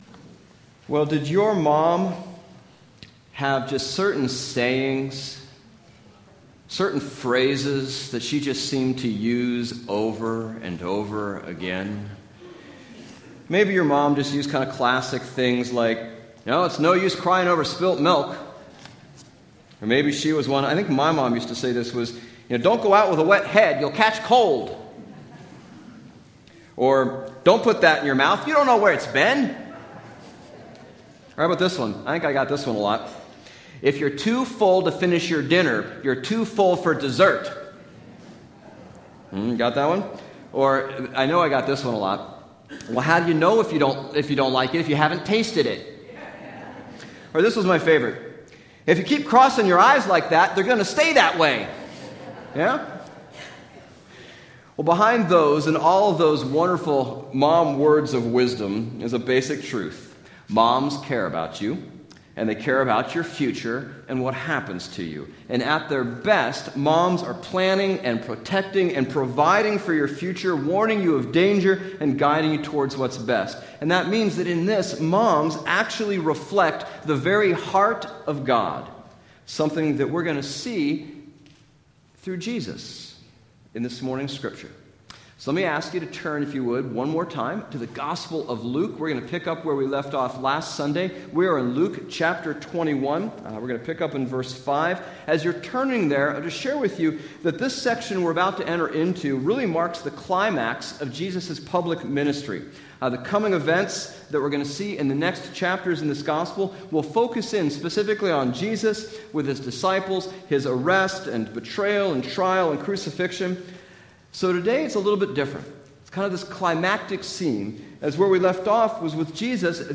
Bible Text: Luke 21:5-38 | Preacher